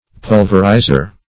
Pulverizer \Pul"ver*i`zer\, n.
pulverizer.mp3